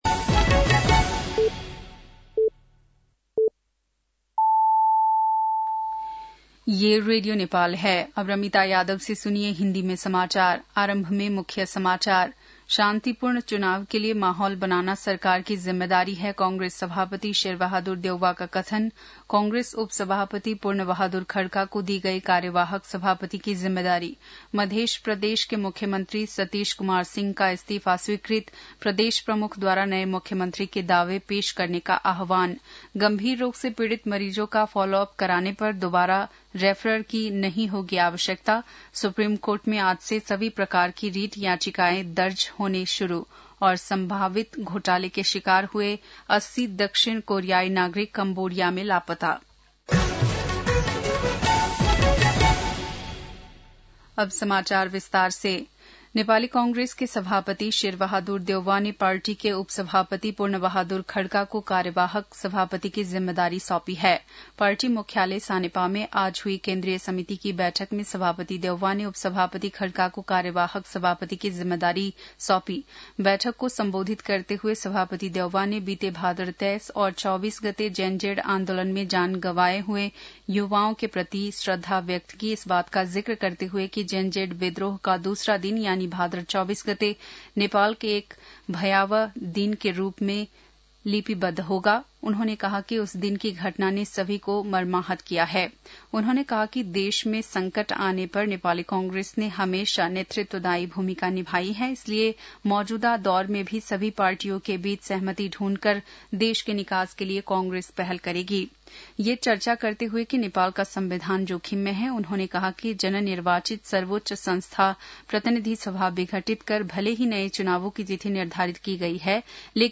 बेलुकी १० बजेको हिन्दी समाचार : २८ असोज , २०८२
10-pm-hindi-news-6-28.mp3